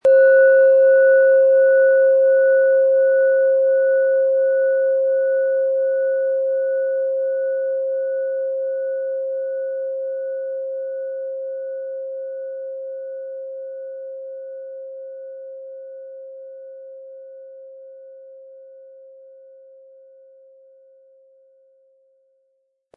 • Mittlerer Ton: Biorhythmus Geist
Unter dem Artikel-Bild finden Sie den Original-Klang dieser Schale im Audio-Player - Jetzt reinhören.
Mit einem sanften Anspiel "zaubern" Sie aus der DNA mit dem beigelegten Klöppel harmonische Töne.
HerstellungIn Handarbeit getrieben
MaterialBronze